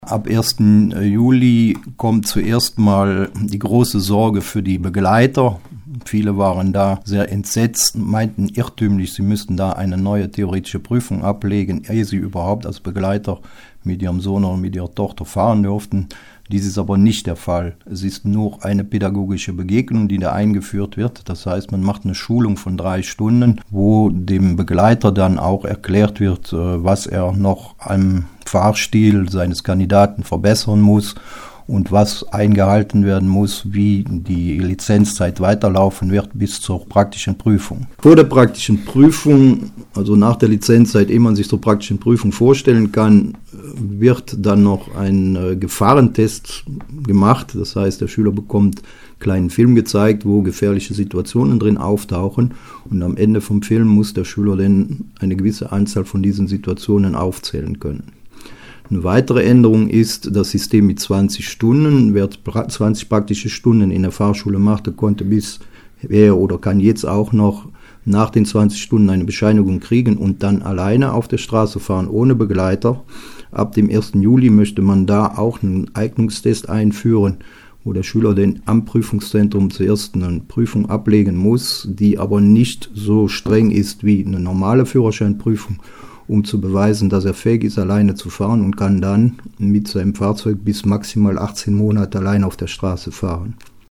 hat sich mit Fahrlehrer